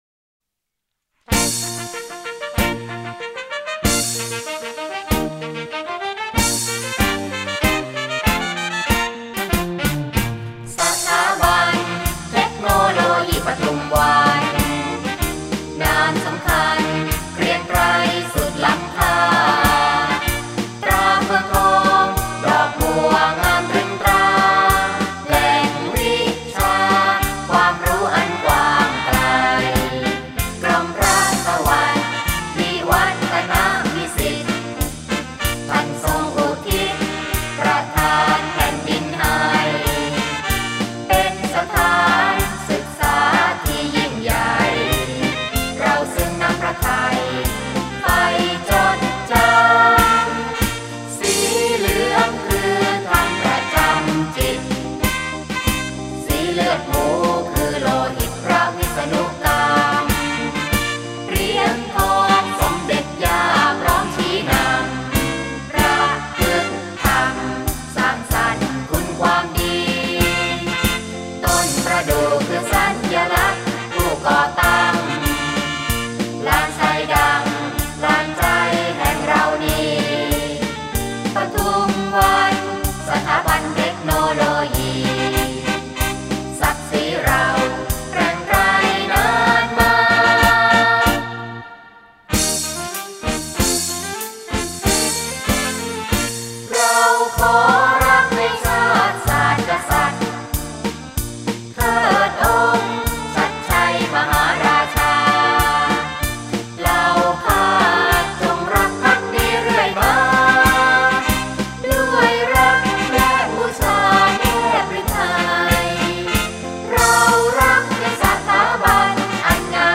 มาร์ชเทคโนโลยีปทุมวัน (ขับร้องหมู่)